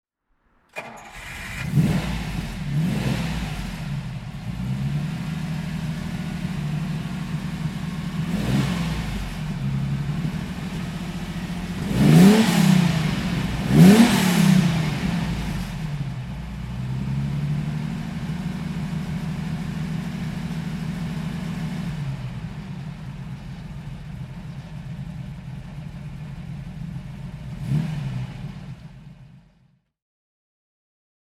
Lamborghini Jarama 400 GTS (1976) - Starten und Leerlauf